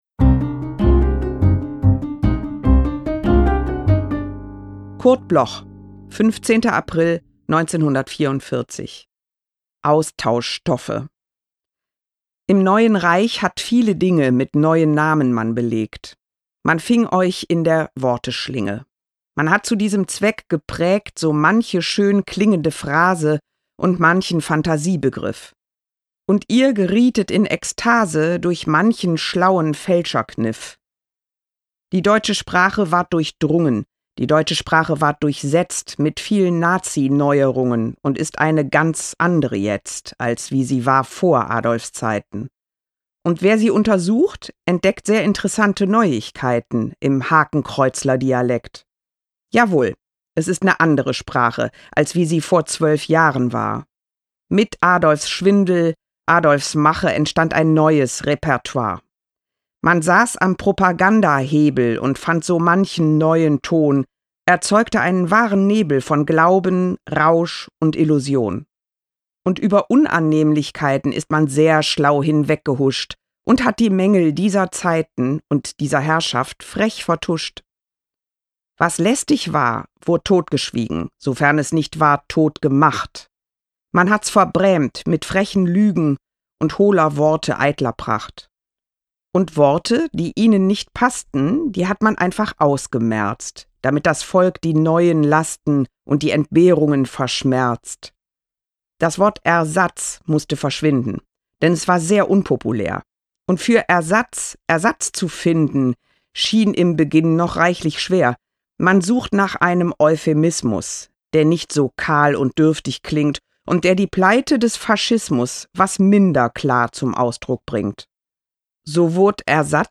Cordula-Stratmann-Austauschstoffe-mit-Musik_raw.m4a